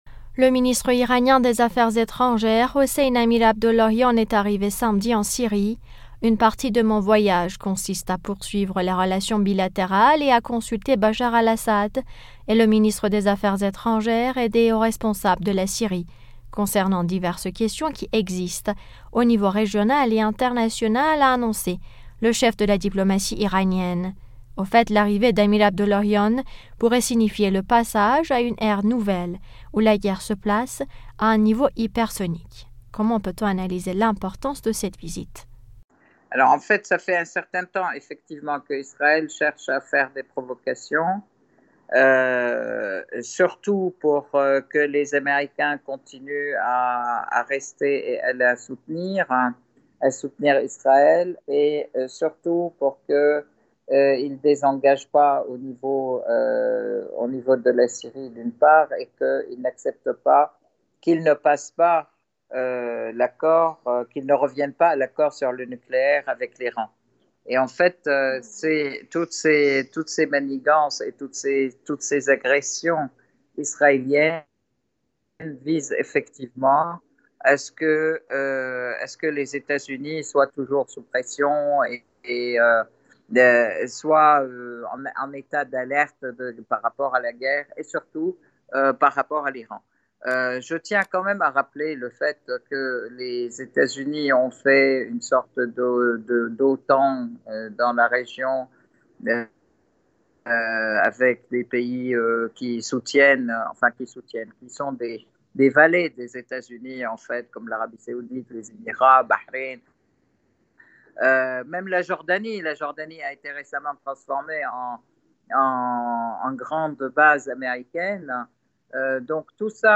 analyste franco-syrienne des questions internationales s'exprime sur le sujet.